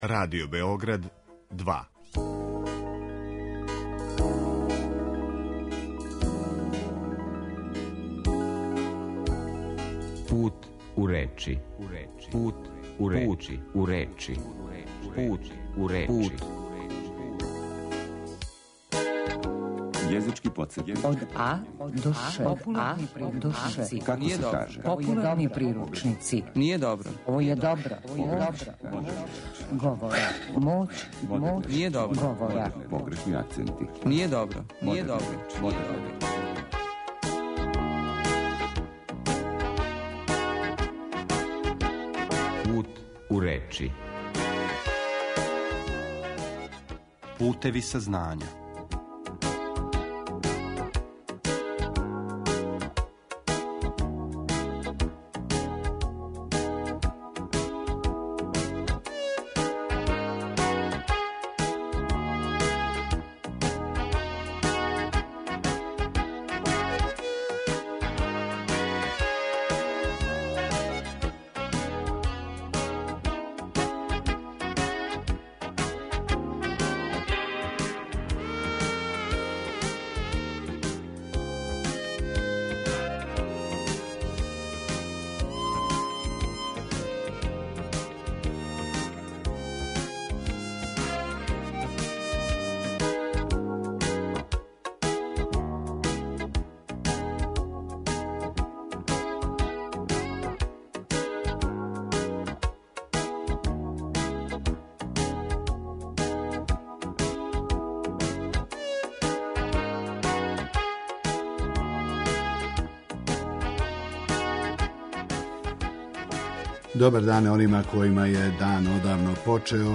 Емисија о лингвистици, нашем књижевном језику у теорији и пракси, свакодневној вербалној комуникацији и говору на медијима.